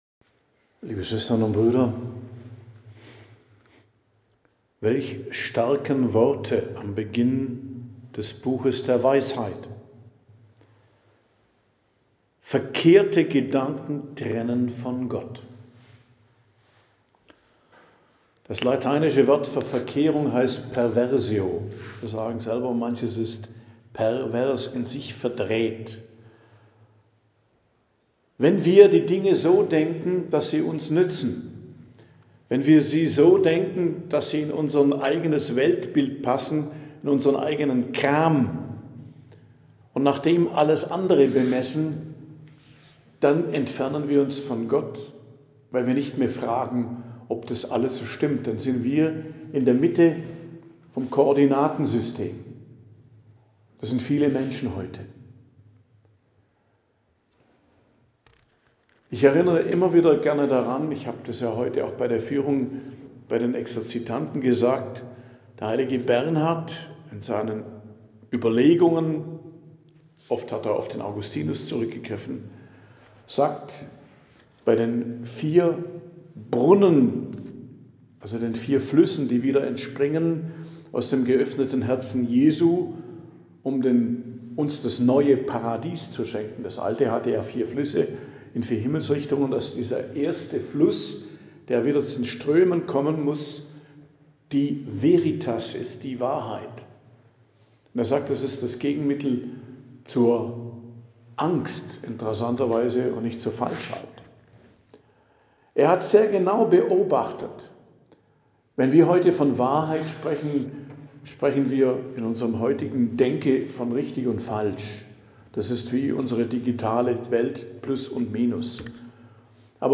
Predigt am Fest des Hl Martin, Dienstag der 32. Woche i.J., 11.11.2025